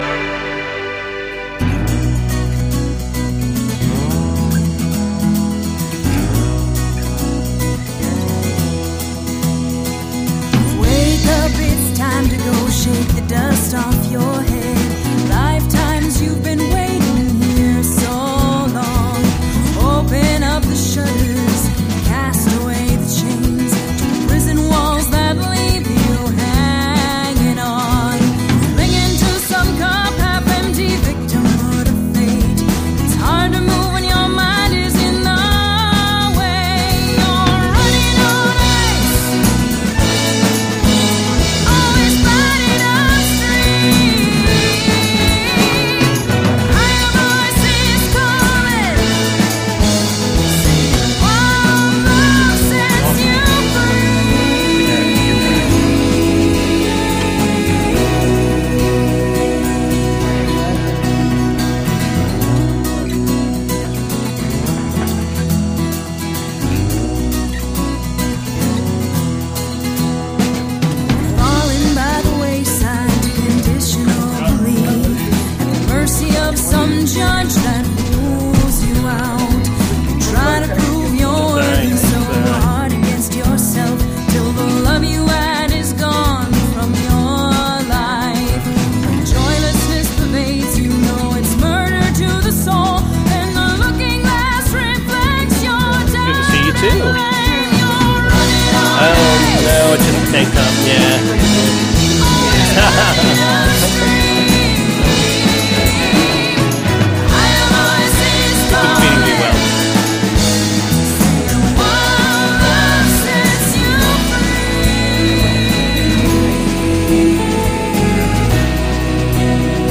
Recorded from Live Broadcast on WSHC Radio at Shepherd University, WV on July 19, 2014